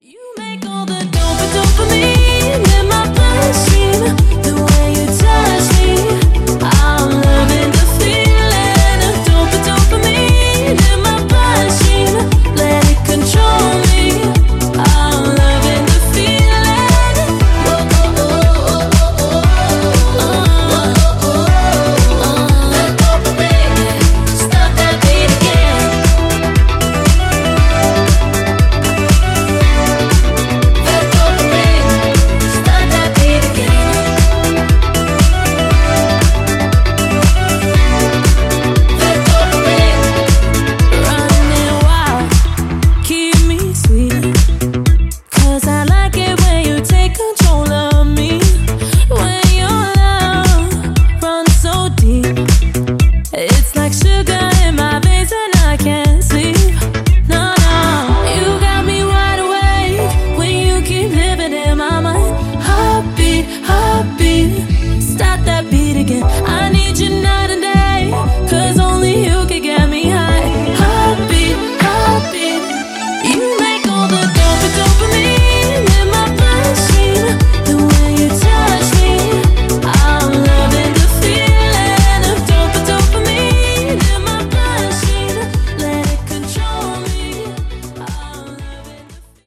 EXTENDED
ジャンル(スタイル) HOUSE / NU DISCO / BALEARIC